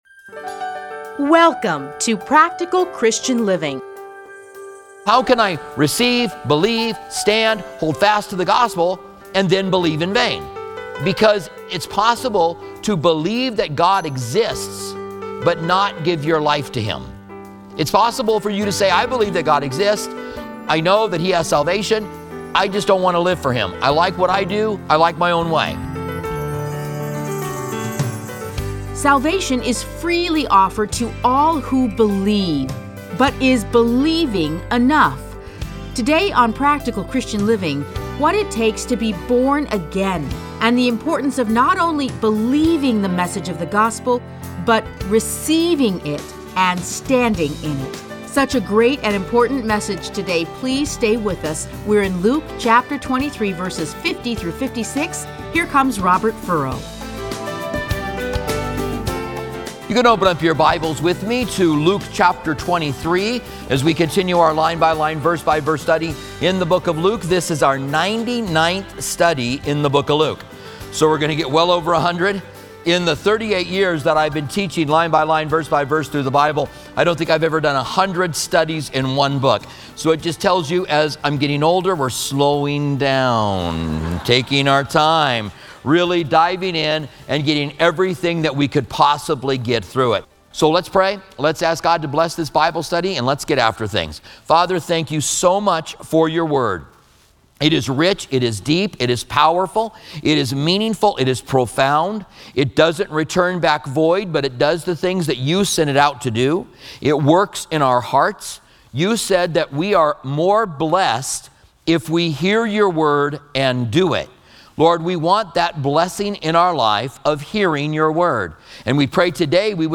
Listen to a teaching from Luke 23:50-56.